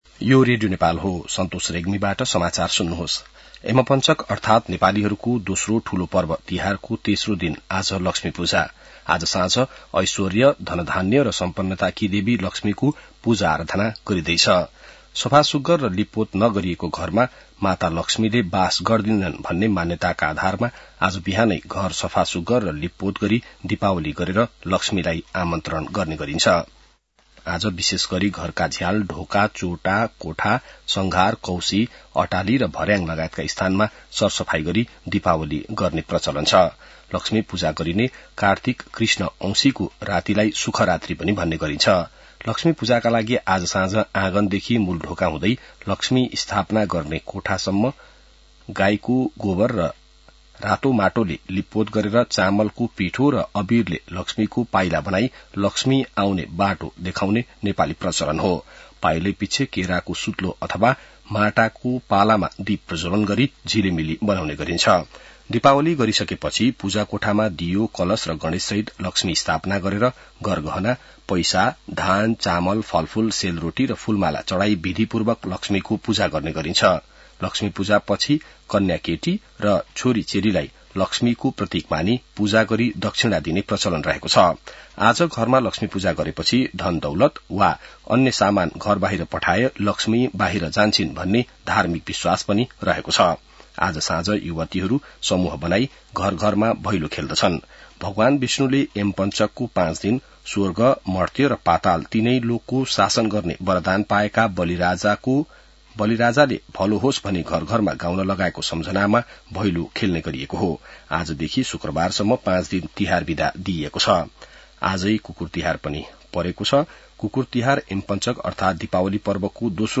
बिहान ६ बजेको नेपाली समाचार : ३ कार्तिक , २०८२